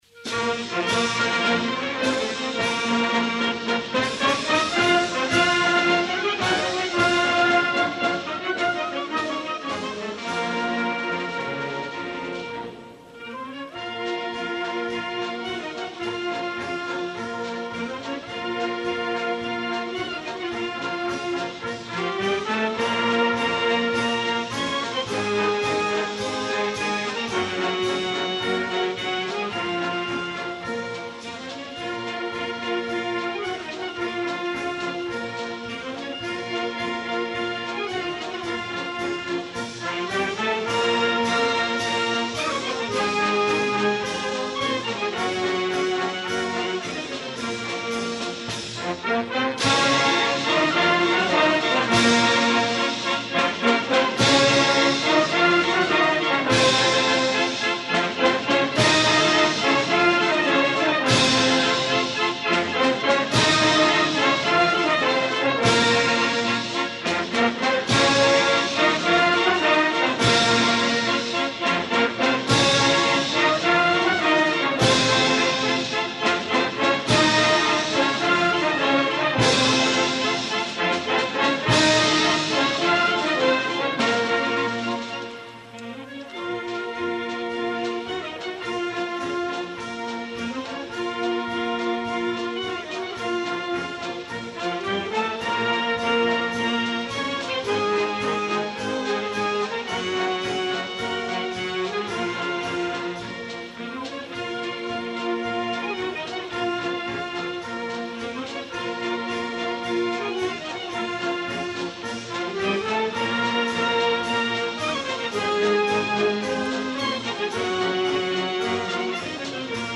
Concert extraordinari 1988. Esglesia parroquial de Porreres Nostra Senyora de la Consolació.